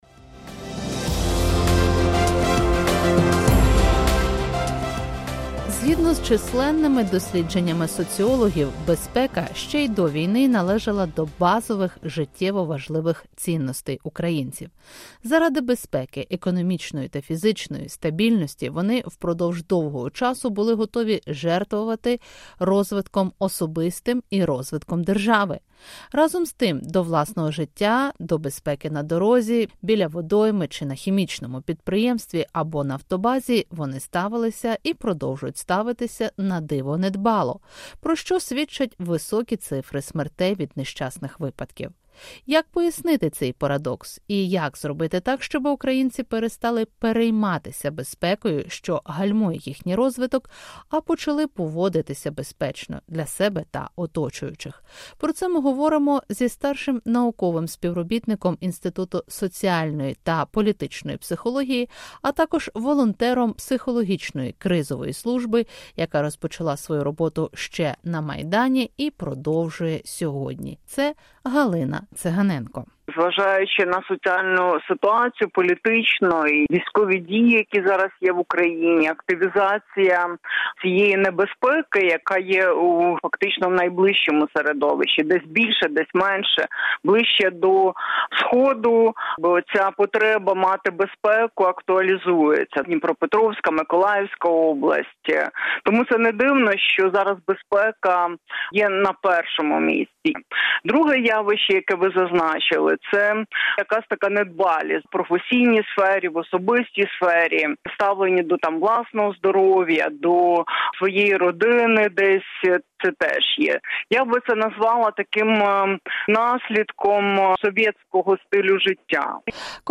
Ми зараз засвоюємо нові форми турботи про себе і своїх близьких – психолог